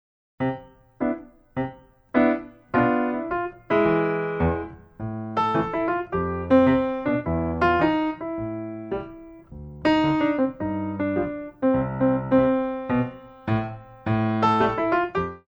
By Pianist & Ballet Accompanist
Tendu